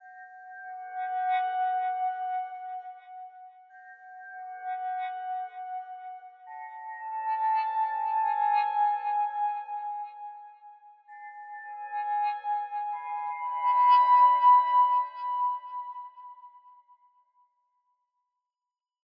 AV_Arrivals_Texture_F#m_130BPM
AV_Arrivals_Texture_Fm_130BPM.wav